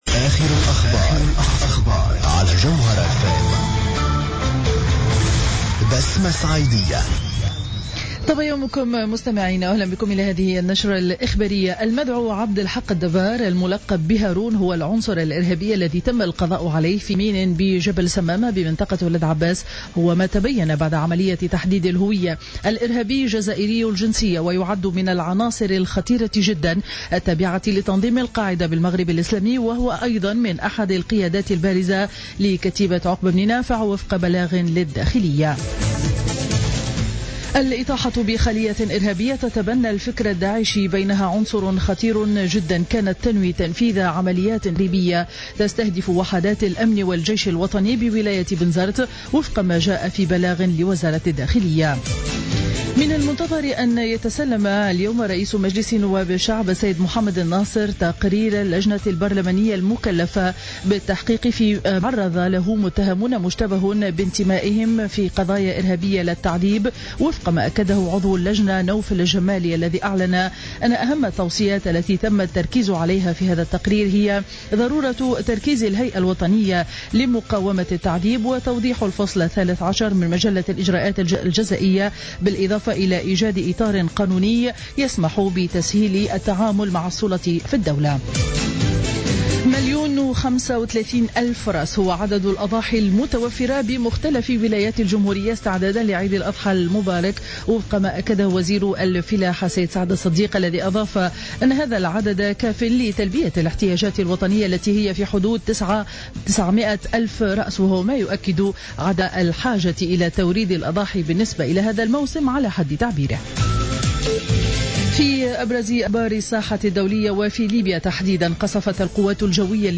نشرة أخبار السابعة صباحا ليوم الاثنين 17 أوت 2015